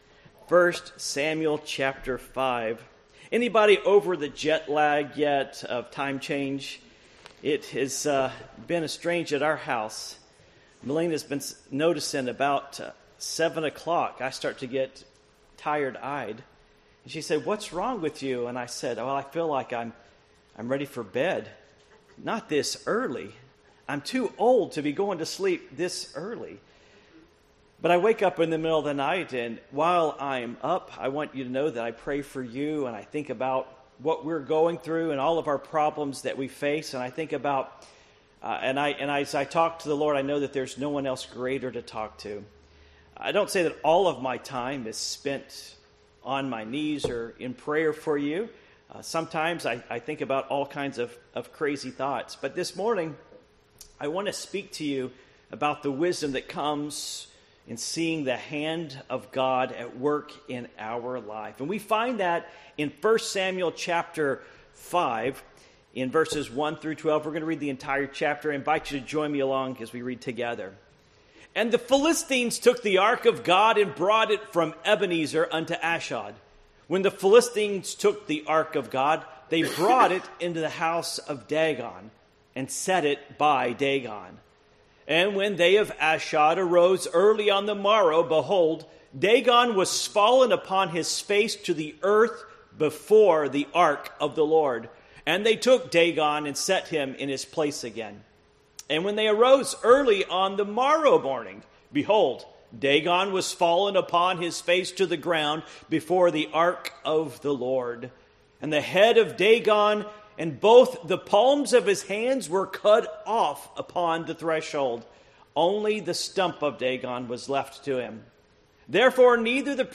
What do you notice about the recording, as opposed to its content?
Passage: 1 Samuel 5:1-12 Service Type: Morning Worship